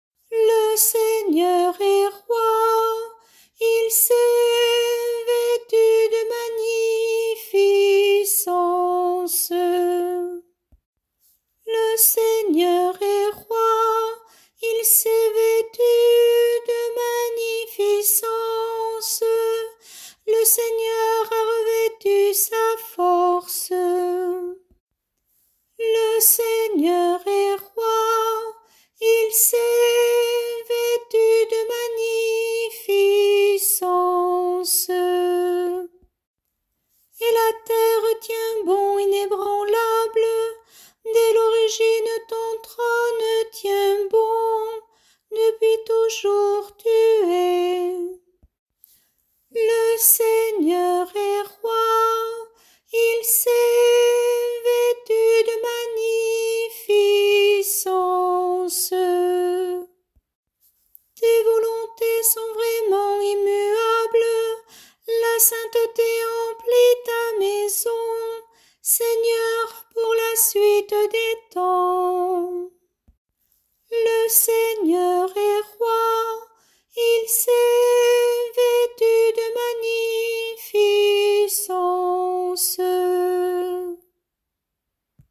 Chorale psaumes année B – Paroisse Aucamville Saint-Loup-Cammas